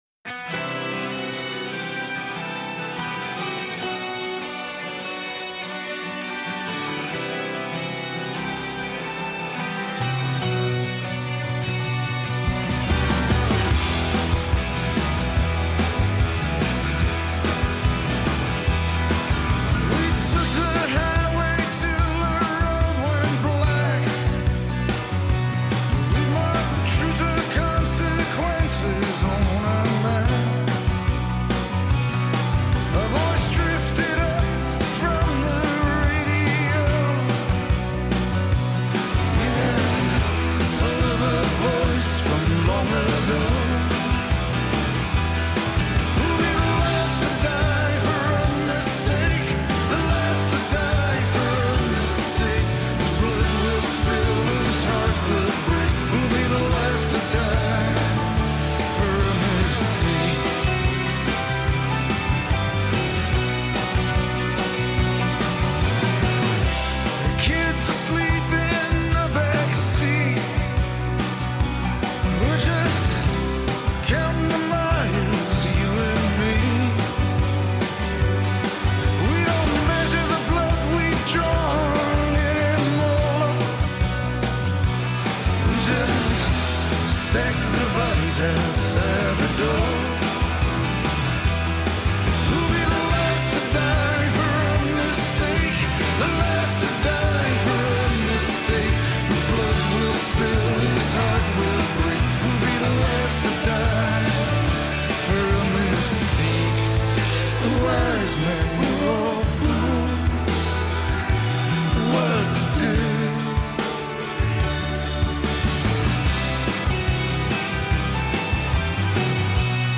آهنگ ها ملوديک هستند و ملودي ها رمانتيک.